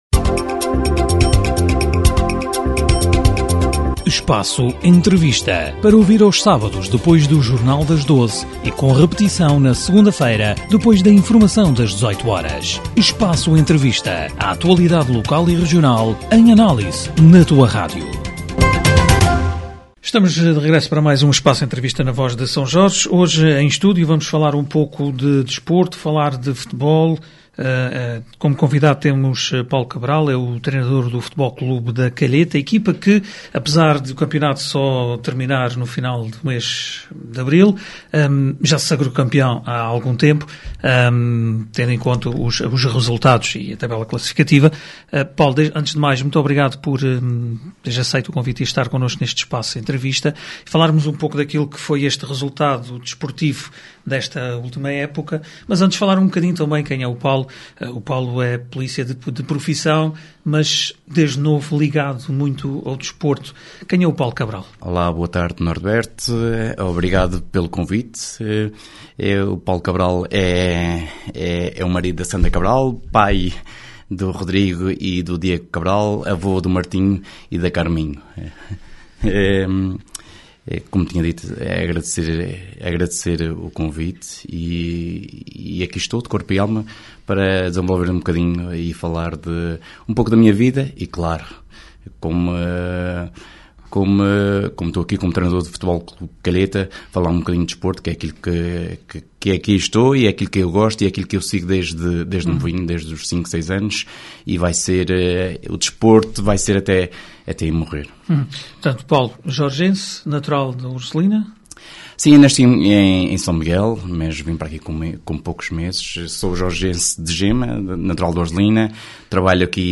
Espaço Entrevista